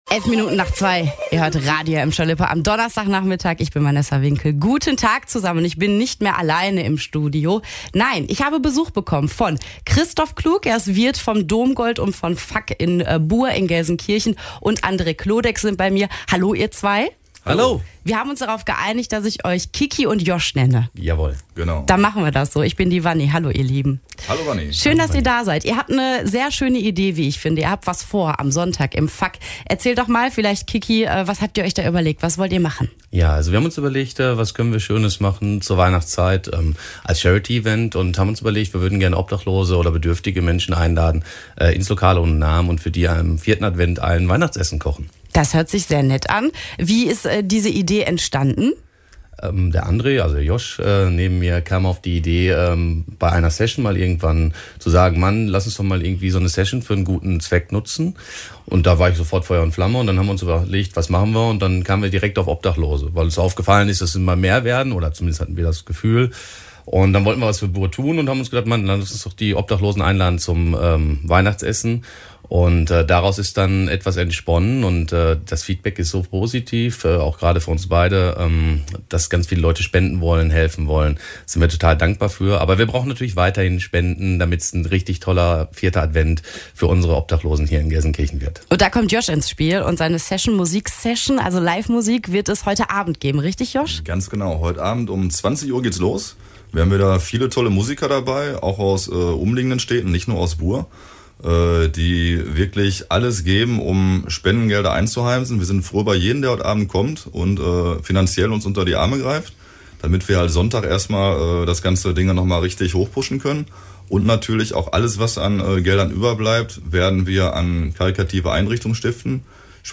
interview-obdachlosenessen-buer.mp3